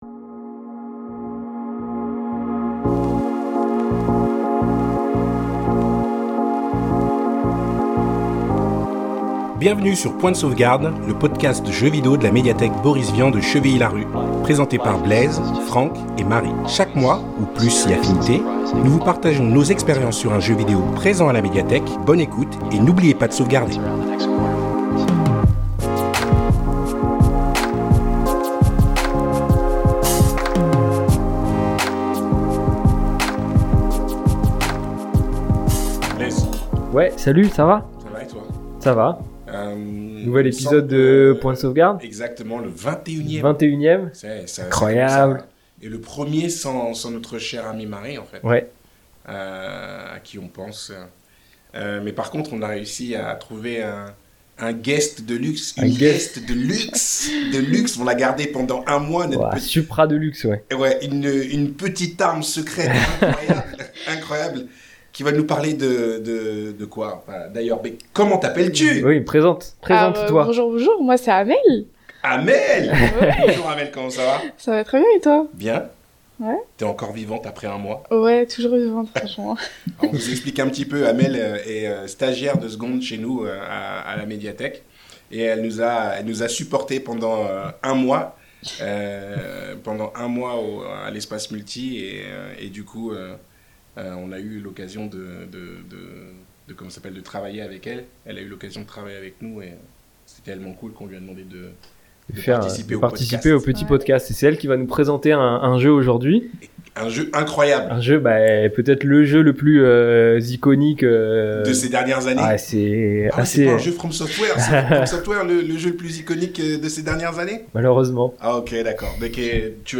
ensemble, ils papotent avec sérieux et humour sur leurs expériences de jeu